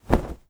FABRIC_Movement_Fast_01_mono.wav